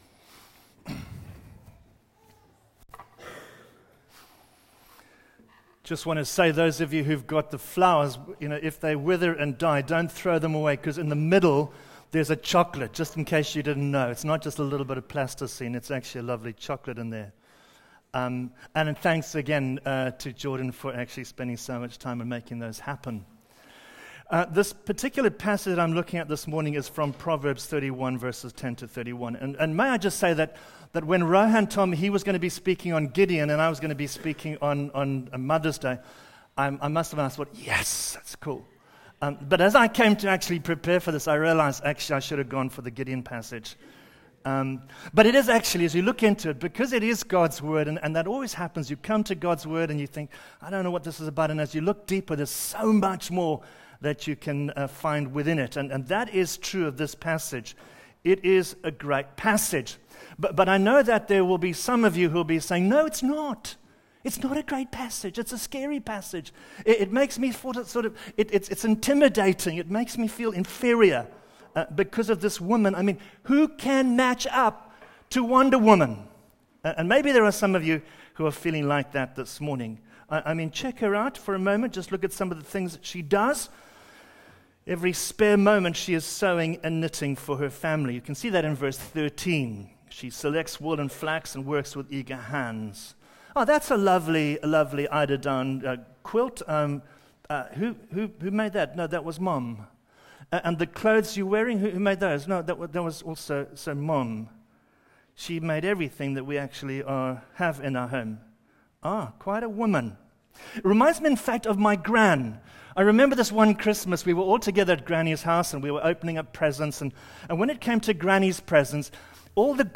Mother’s Day Service